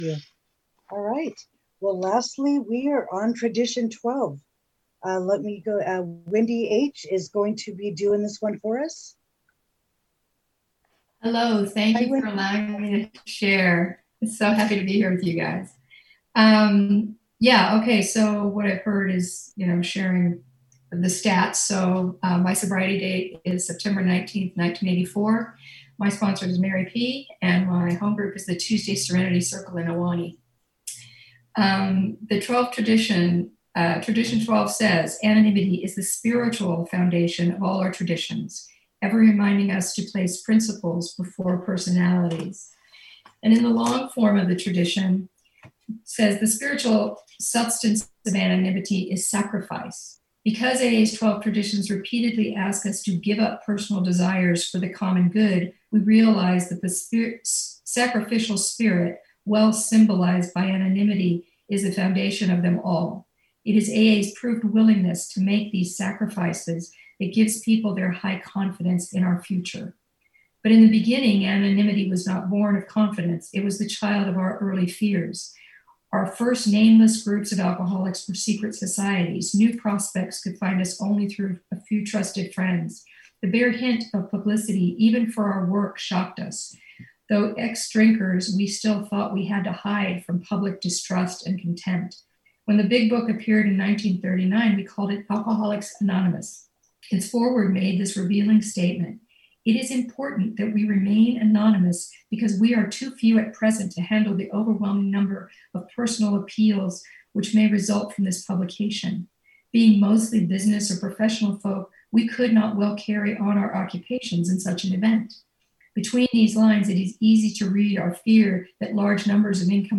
CNIA DISTRICT 40 41 42 AND 43 TRADITIONS WORKSHOP